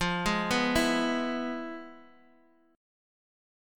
E/F chord {x x 3 1 0 0} chord